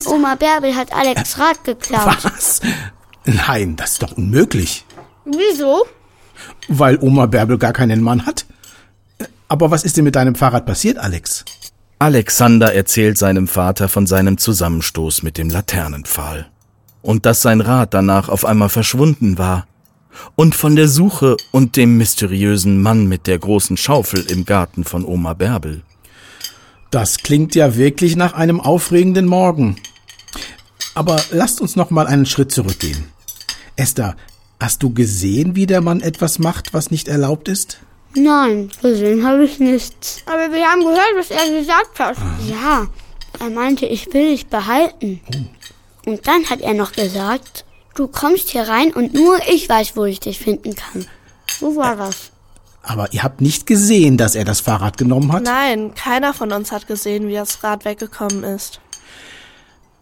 (Hörbuch/Hörspiel - CD)
Hörspiele